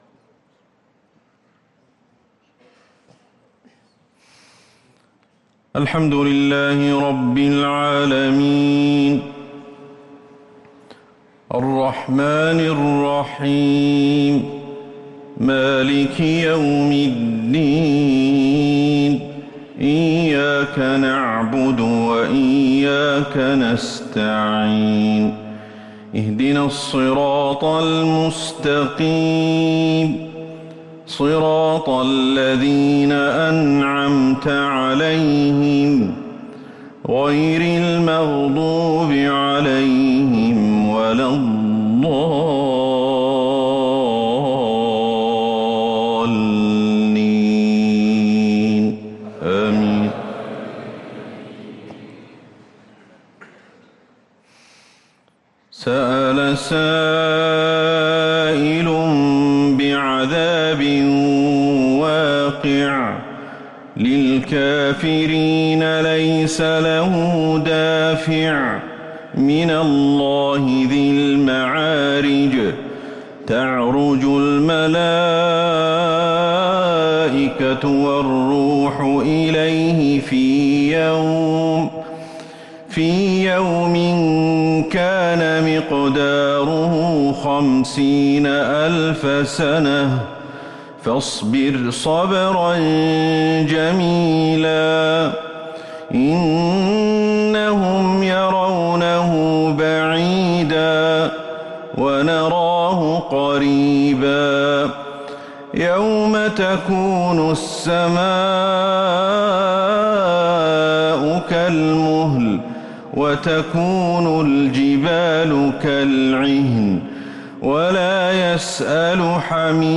صلاة العشاء
تِلَاوَات الْحَرَمَيْن .